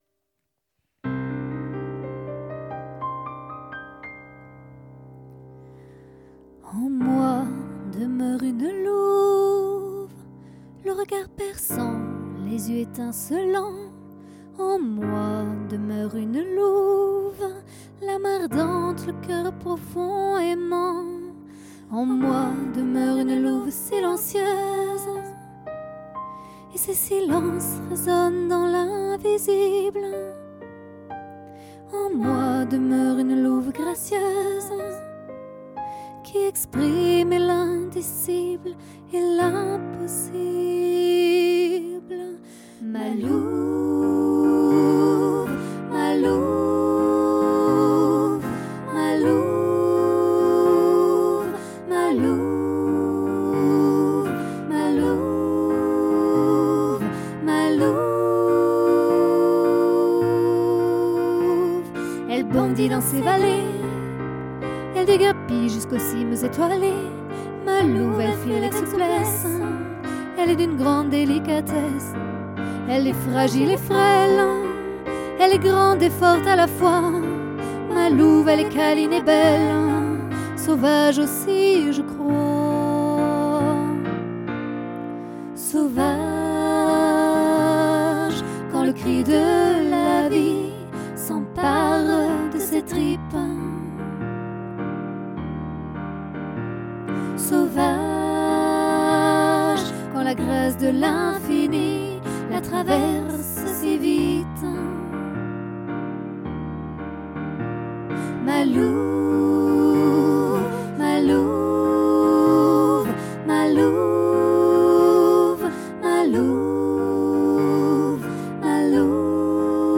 texte et chanson
louve_remix.mp3